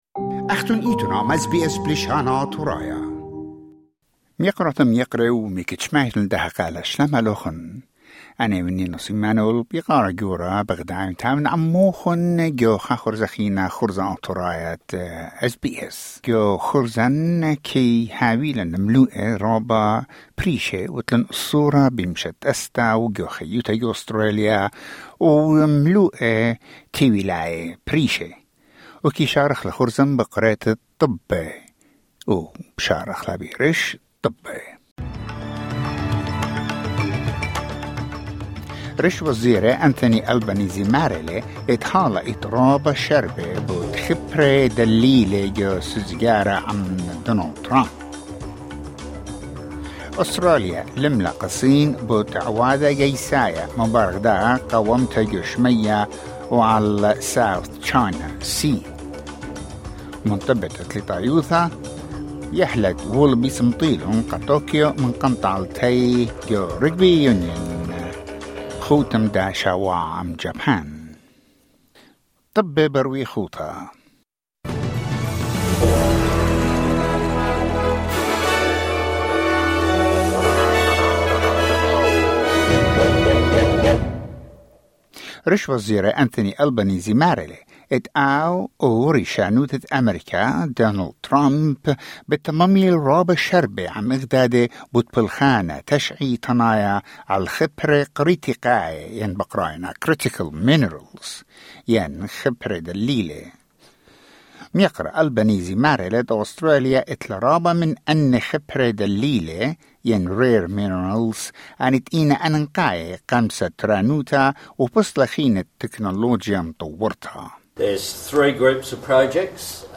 News Bulletin: 21 October 2025